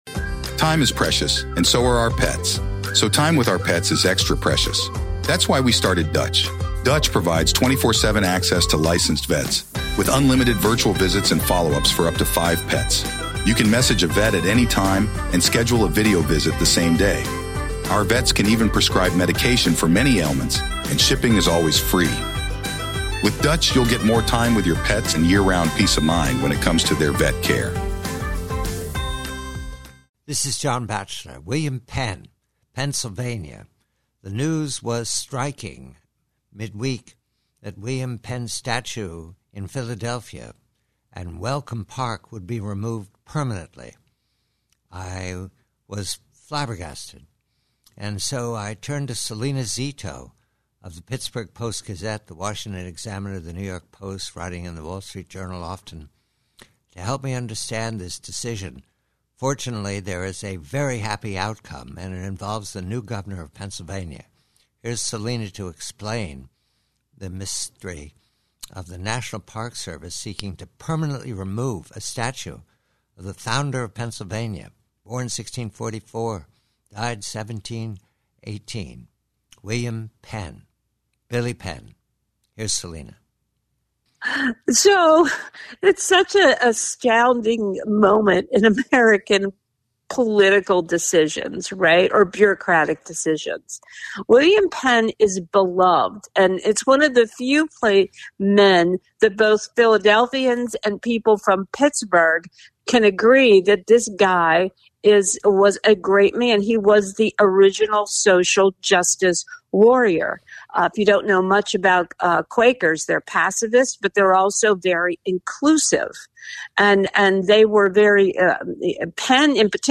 PREVIEW: Excerpt from a conversation with Salena Zito of the Washington Examiner about the mystery of the National Park Service threat to remove permanently a statue of William Penn from a Philadelphia park -- and the happy ending thanks to the swift auth